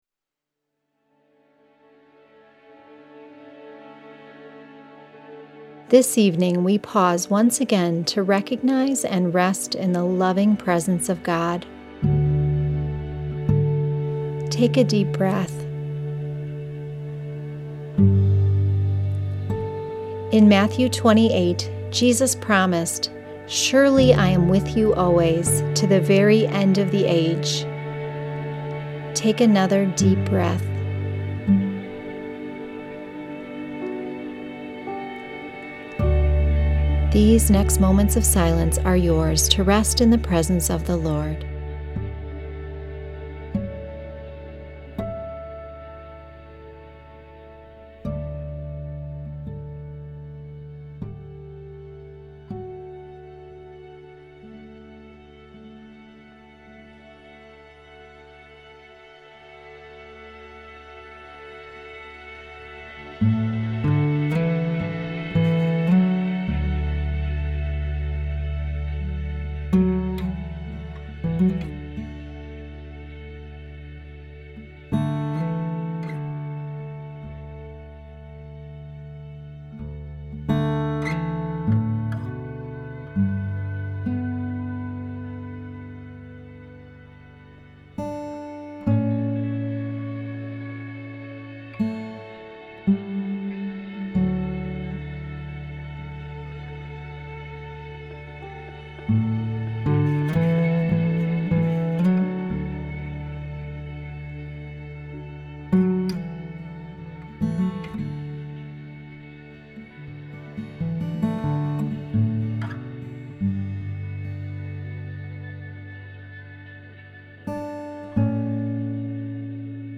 Below you will find a series of guided prayers.
Each prayer begins in silence.